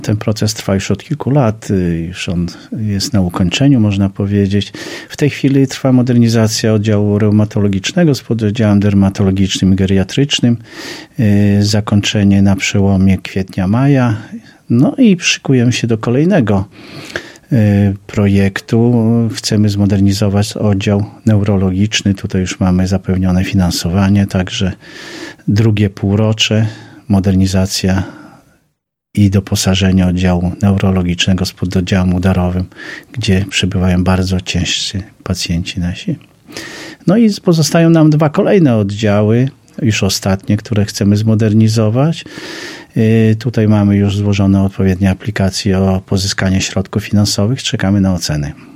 O inwestycjach mówił w czwartek (23.01.20) w Radiu 5